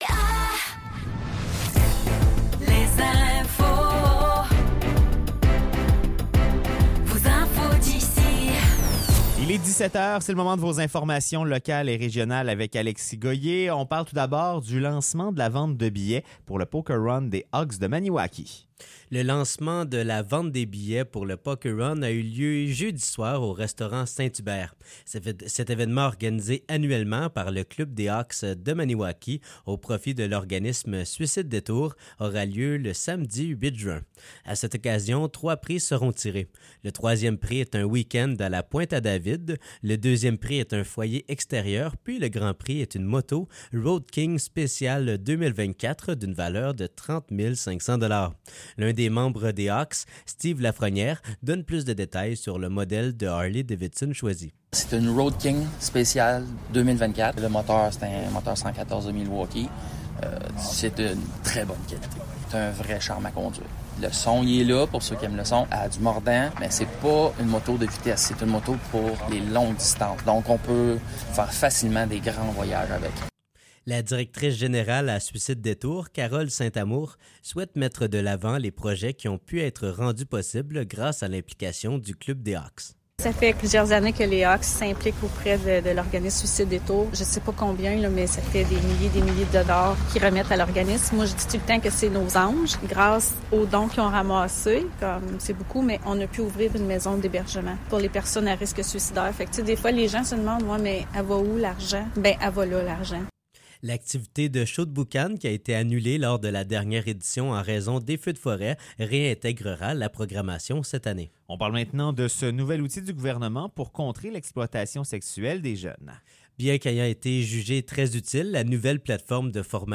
Nouvelles locales - 12 février 2024 - 17 h